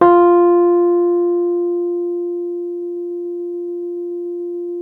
RHODES CL0CL.wav